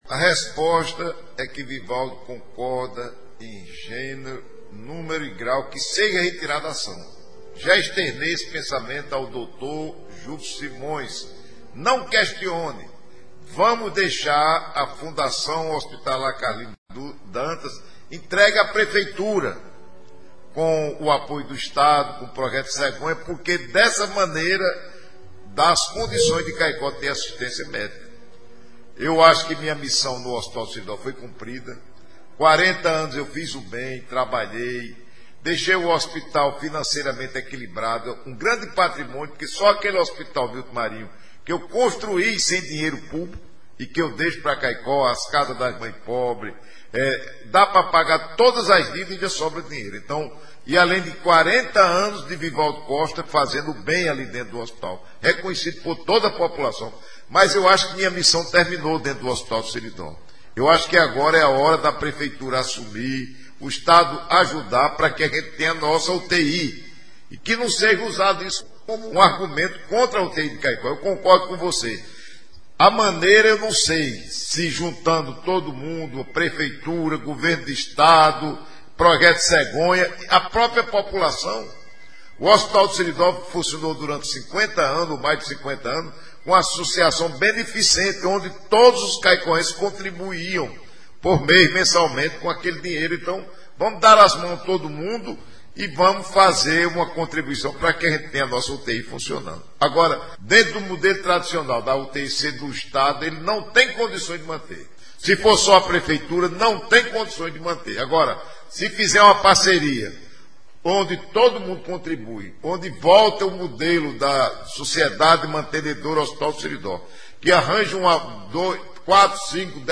Em seu programa de Rádio na manhã deste sábado (10), o deputado estadual Vivaldo Costa, presidente de honra da Fundação Carlindo Dantas, disse que já determinou aos seus advogados que desistam da ação judicial que tramita em grau de recurso para garantir a natureza jurídica do Hospital.